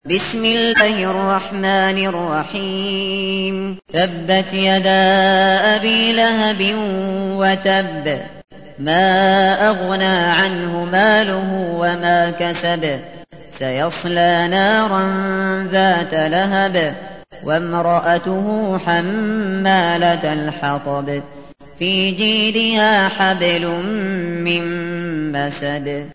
تحميل : 111. سورة المسد / القارئ الشاطري / القرآن الكريم / موقع يا حسين